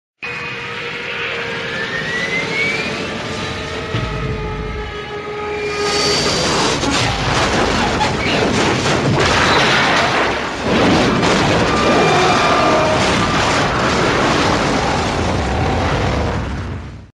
Tiếng Máy Bay Rơi
Thể loại: Tiếng xe cộ
Description: Tải về tiếng máy bay rơi, âm thanh tai nạn hàng không, tiếng động cơ máy bay gầm rú rồi lao xuống, hiệu ứng tiếng rơi tự do, hoặc âm thanh va chạm từ trên cao – tất cả được tái hiện chân thực, sống động trong file âm thanh này.
tieng-may-bay-roi-www_tiengdong_com.mp3